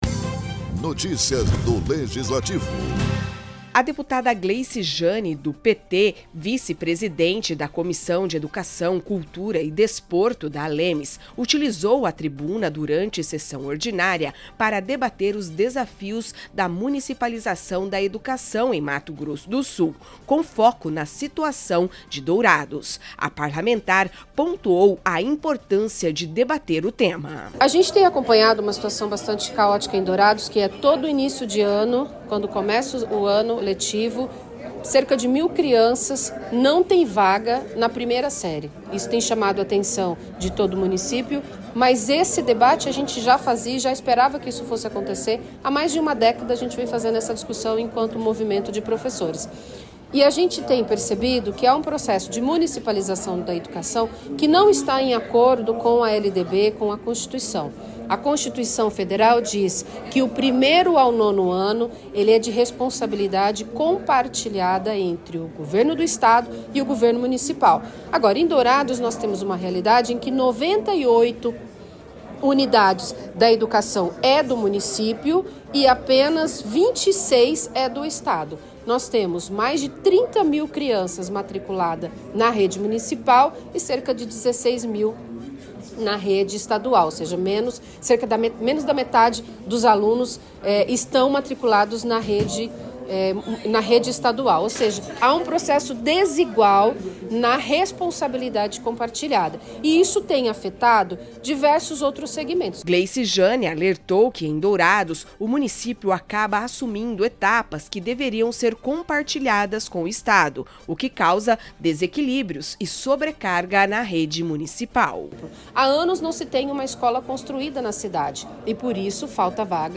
Na tribuna da Assembleia Legislativa, a deputada Gleice Jane (PT) chamou a atenção para a situação da rede pública de ensino em Dourados. Segundo a parlamentar, o processo de municipalização da educação está sobrecarregando o município e prejudicando a qualidade do ensino.